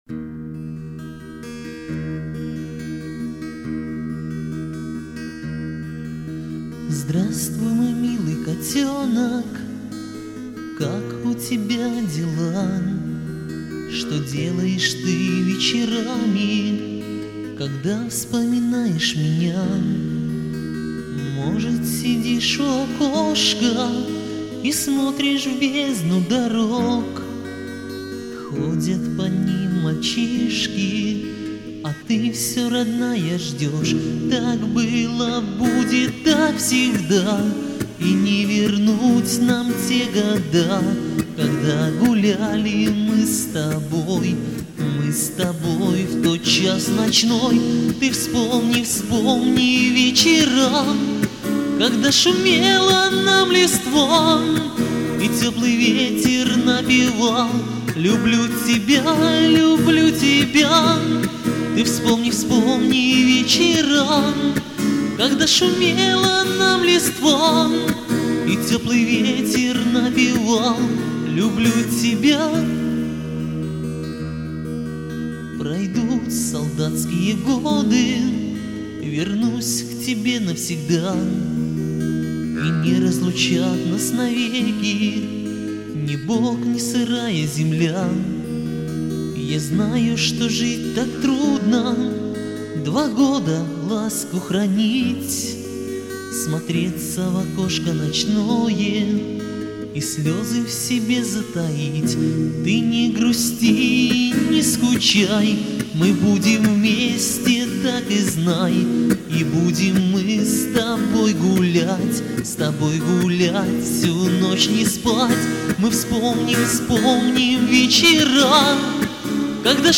armejskie_pesni_kotenok.mp3